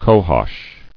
[co·hosh]